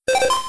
pokegear_on.wav